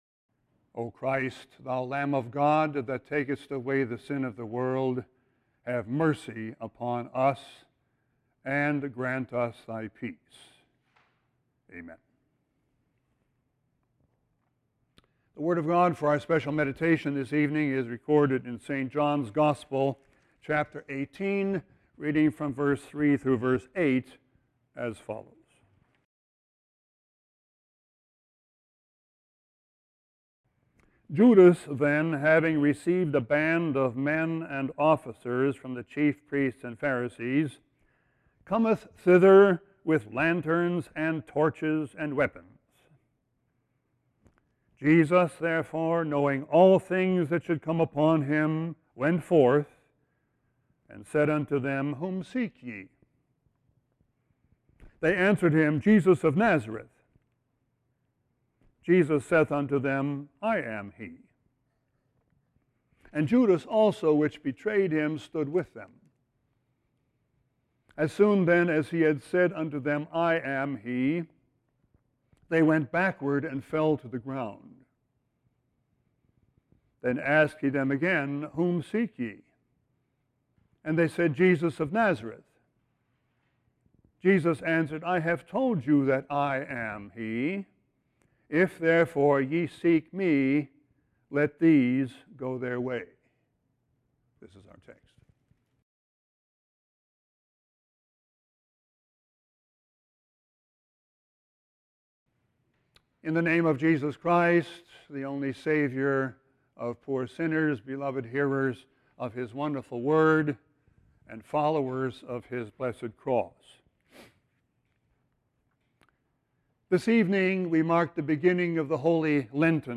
Sermon 2-10-16.mp3